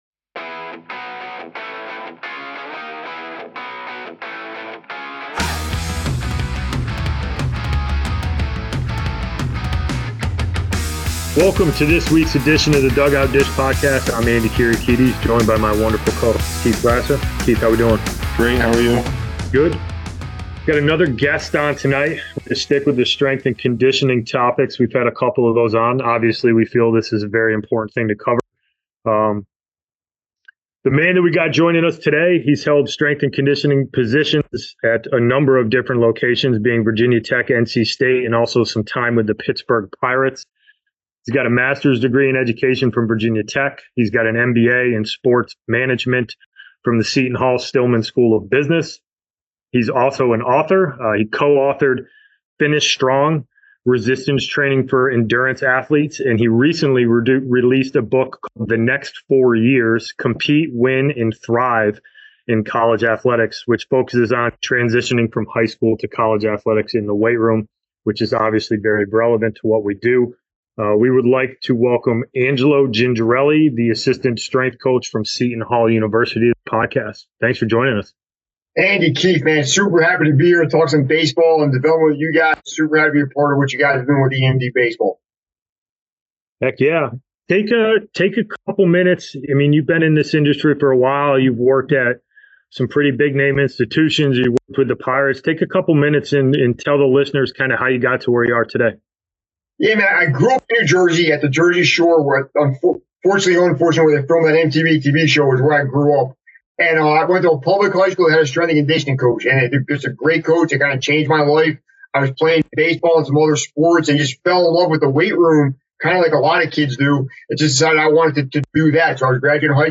Episode 101: Interview